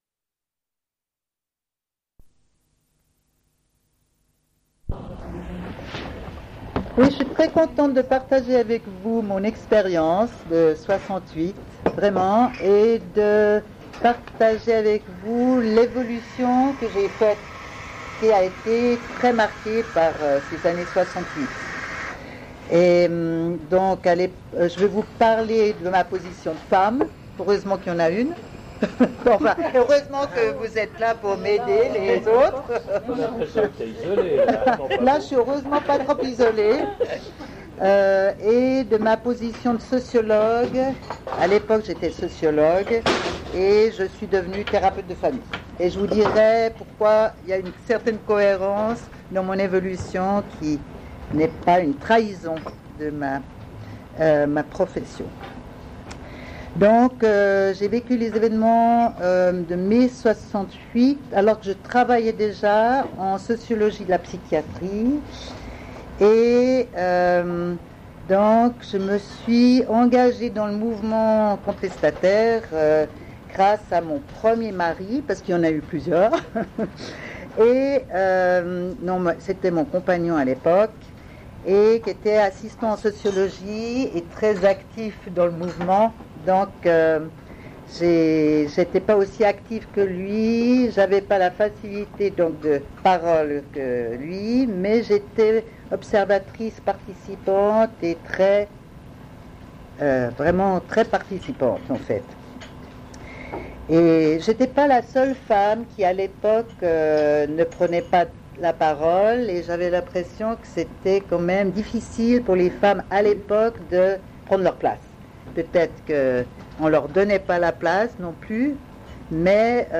Une cassette audio
Discussion avec le public
Témoignage